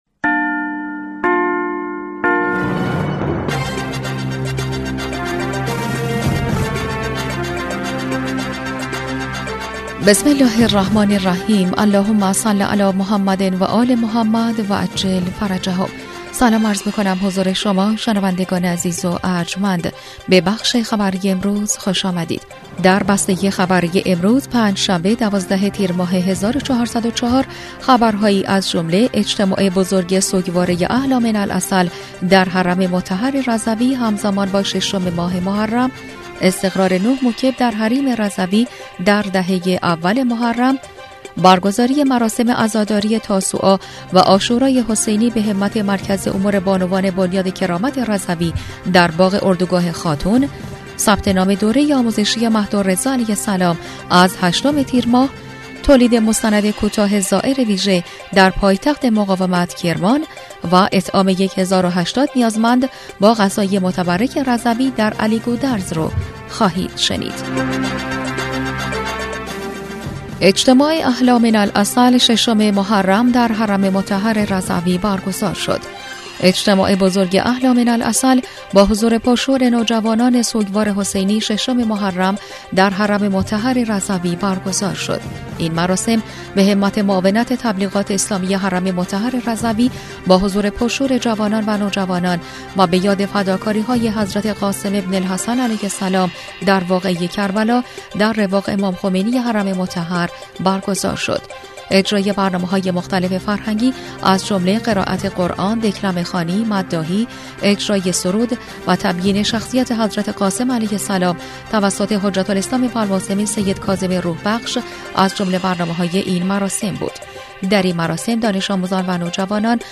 بسته خبری ۱۲ تیرماه ۱۴۰۴ رادیو رضوی/